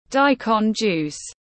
Nước ép củ cải trắng tiếng anh gọi là daikon juice, phiên âm tiếng anh đọc là /ˈdaɪ.kɒn ˌdʒuːs/
Daikon juice /ˈdaɪ.kɒn ˌdʒuːs/